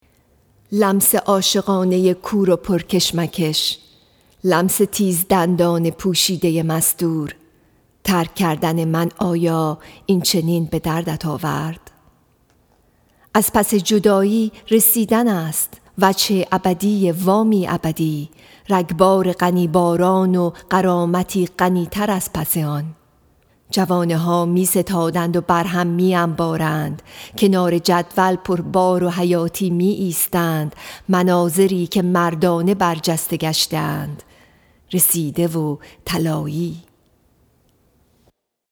Song of Myself, Section 29 —poem read